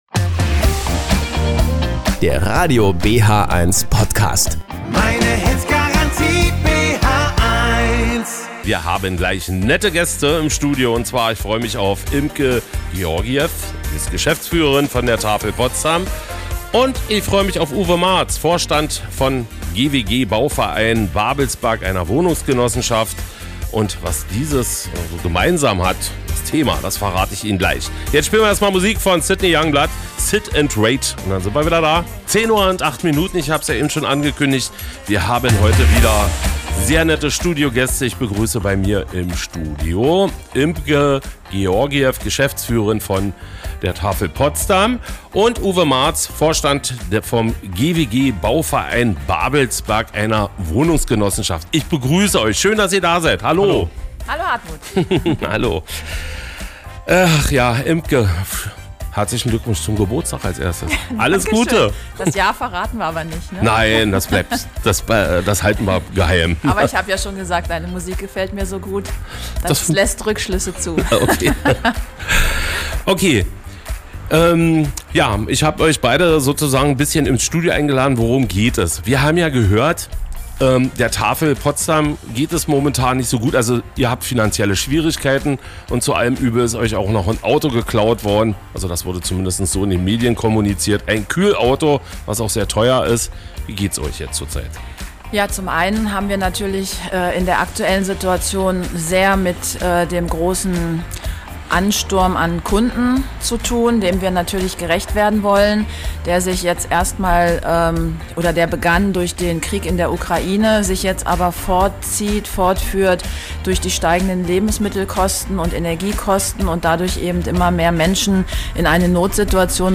Ein Interview bei Radio BHeins